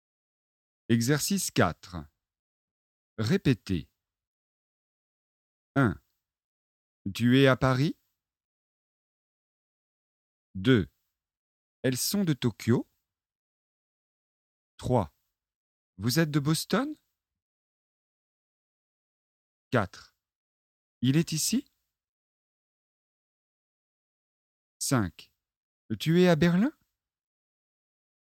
À la forme interrogative, la voix monte.